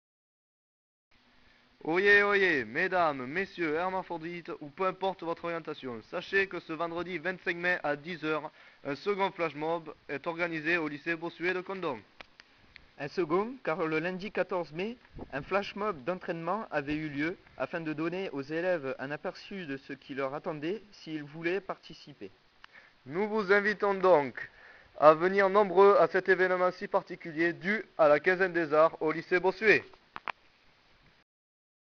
La Quinzaine des Arts du lycée Bossuet a débuté aujourd'hui par un flashmob dans la prairie.